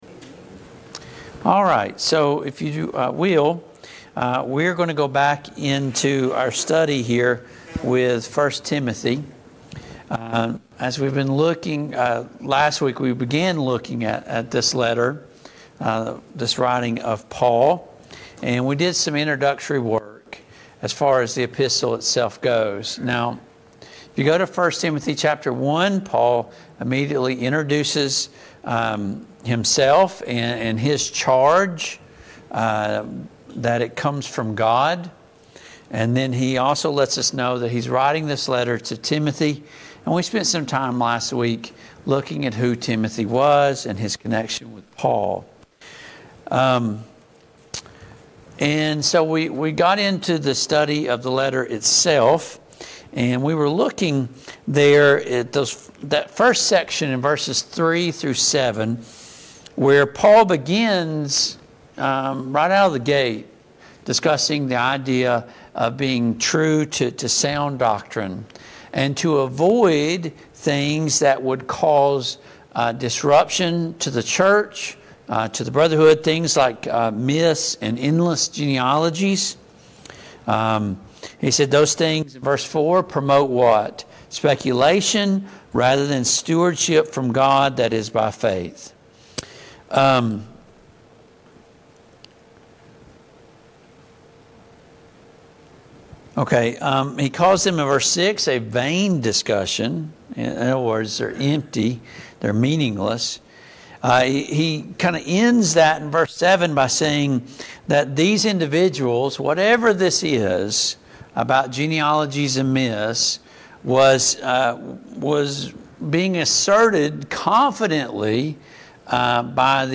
Passage: 1 Timothy 1:3-17 Service Type: Mid-Week Bible Study